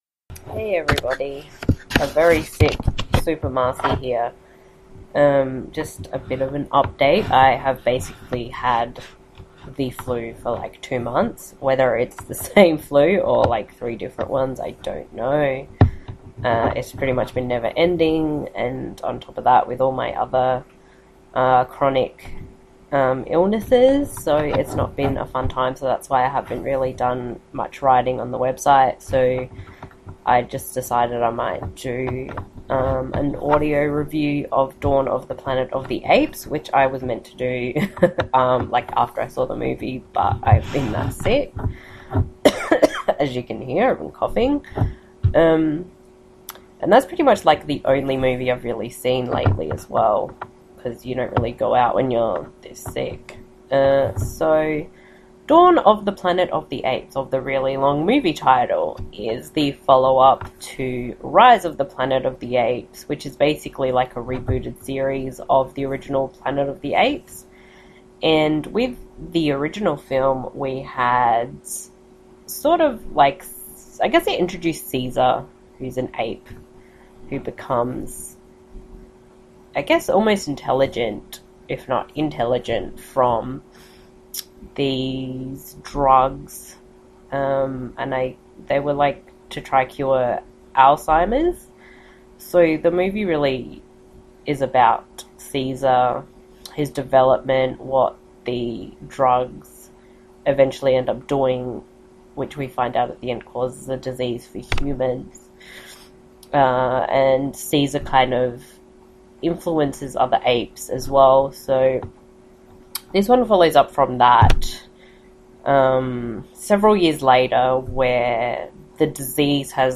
[Audio Review] Dawn Of The Planet Of The Apes (2014)
So sit back and listen and enjoy my lovely flu ridden voice.
dawn-of-the-planet-of-the-apes-audio-review.mp3